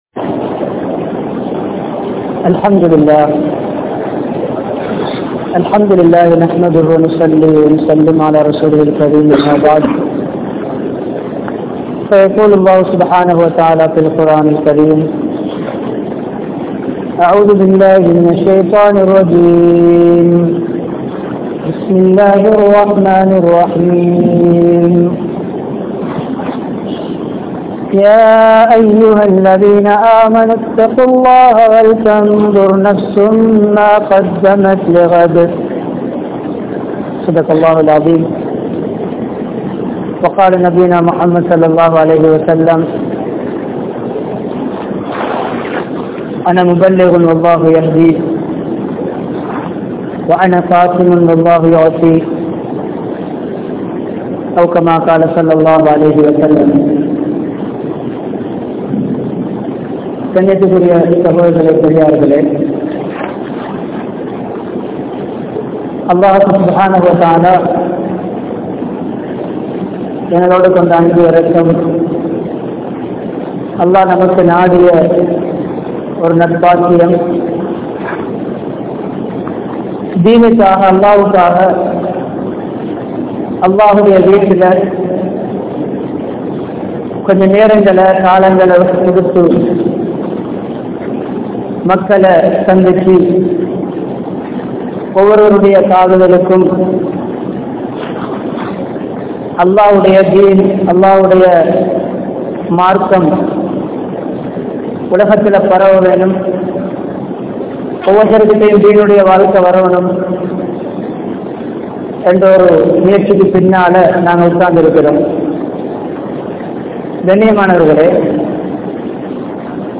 Dhauwaththin Payangal (தஃவத்தின் பயன்கள்) | Audio Bayans | All Ceylon Muslim Youth Community | Addalaichenai